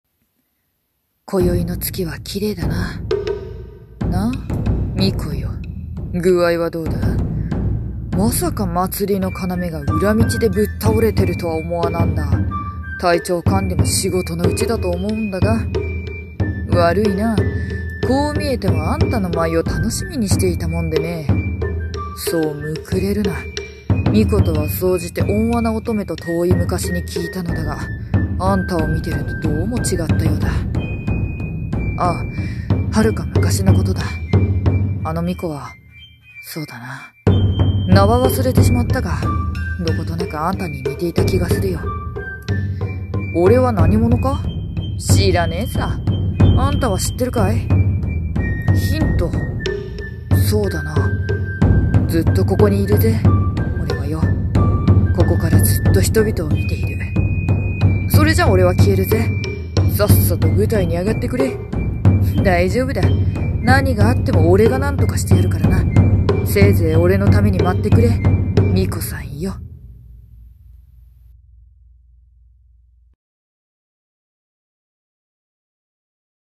【声劇台本】月と巫女と、それから。